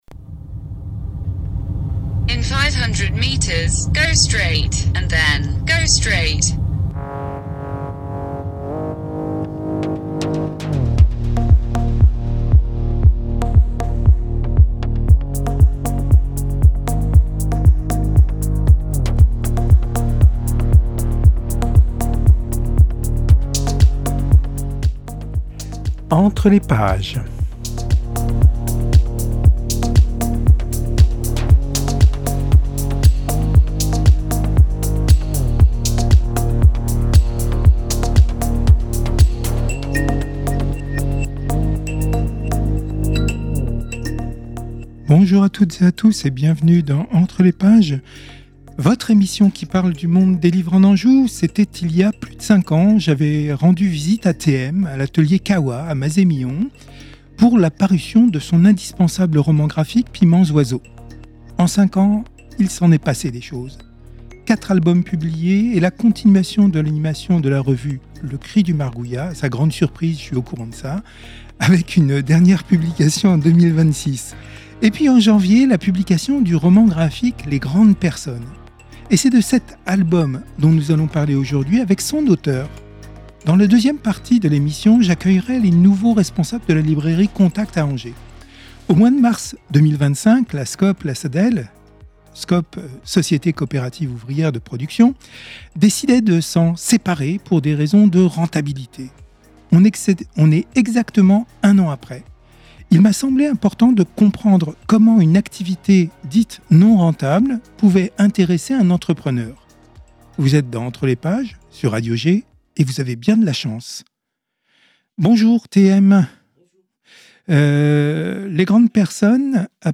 ENTRE LES PAGES, c’est une heure consacrée à l’univers des livres en Anjou. Interviews, reportages, enquêtes, sont au menu.